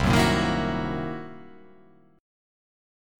C7#9b5 chord